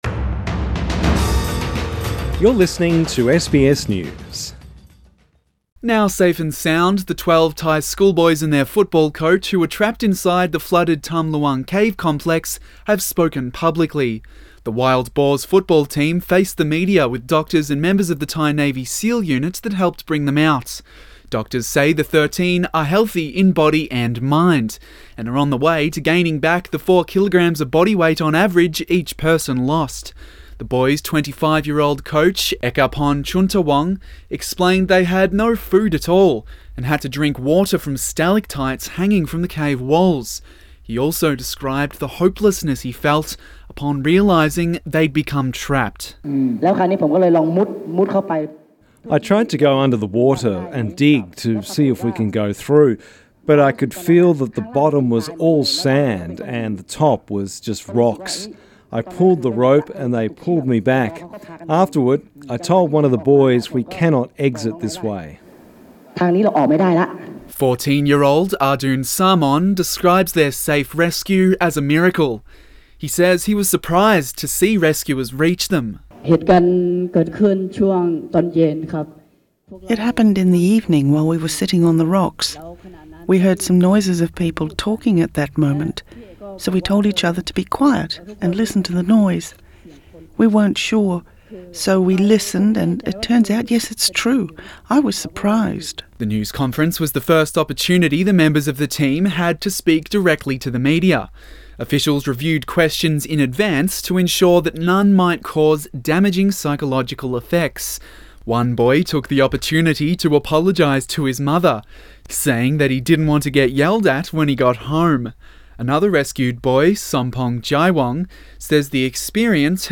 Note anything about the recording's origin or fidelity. The Wild Boars football team looked healthy and happy as they spoke publicly in Chiang Rai, just hours after being discharged from hospital.